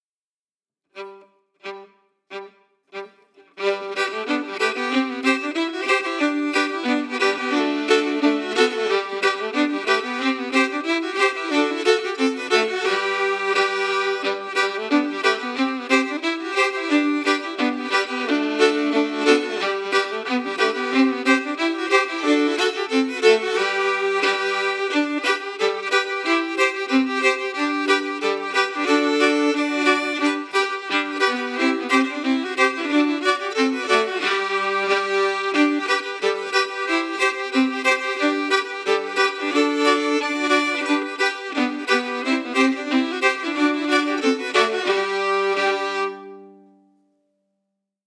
Key: G
Form: Reel
Harmony emphasis
Region: Appalachia